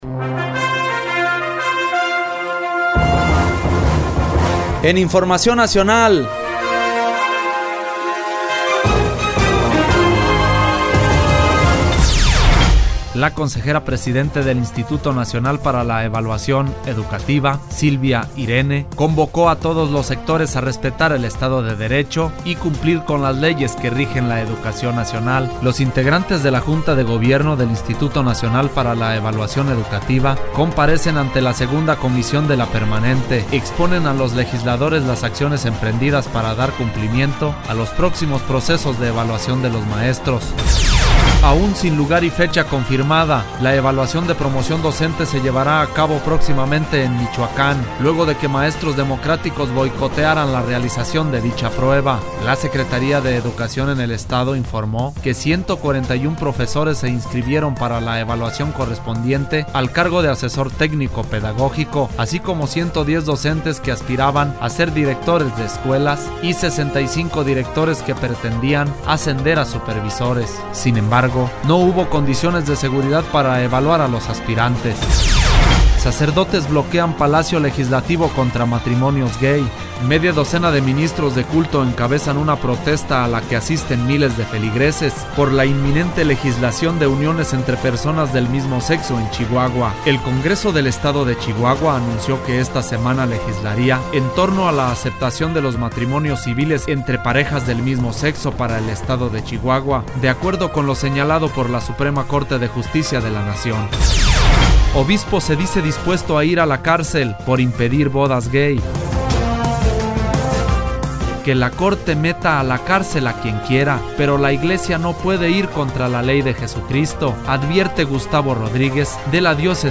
Noticieros-GCI